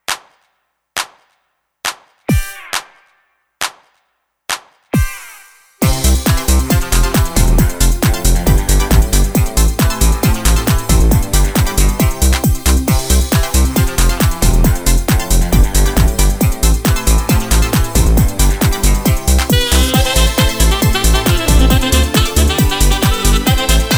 no Backing Vocals Comedy/Novelty 2:54 Buy £1.50